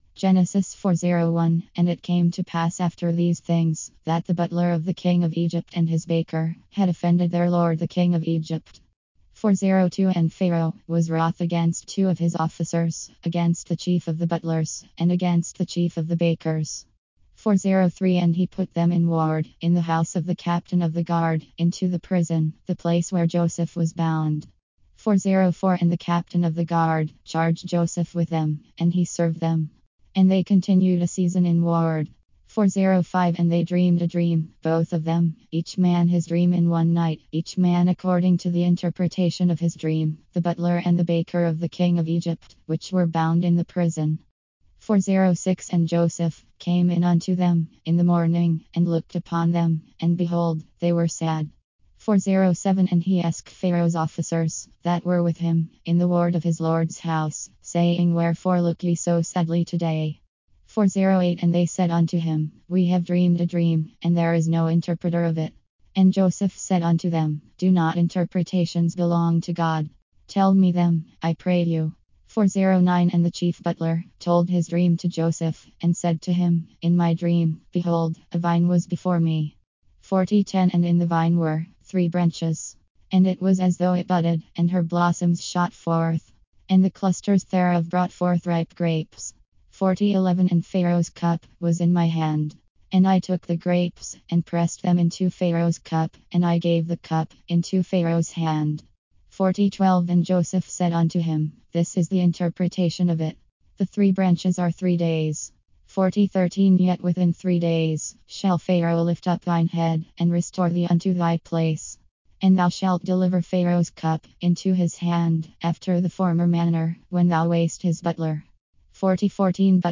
KJV Spoken Word Index